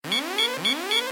missilewarn.ogg